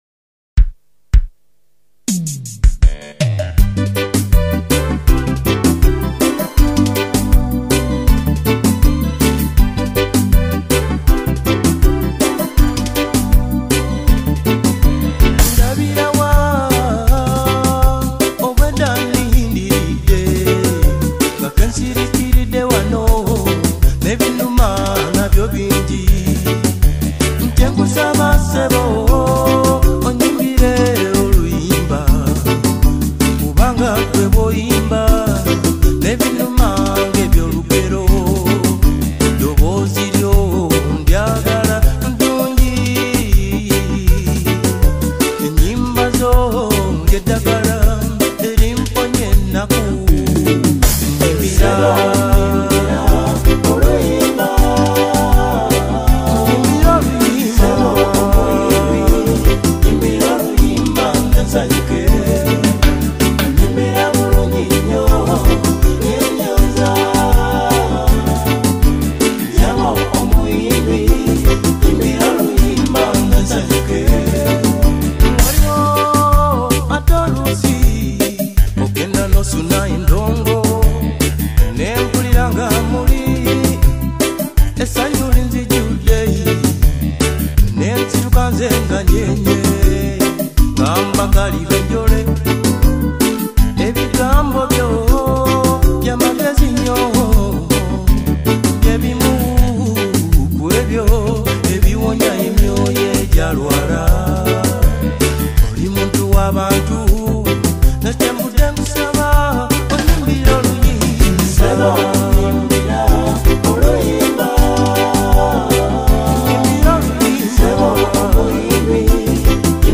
Genre: Ragga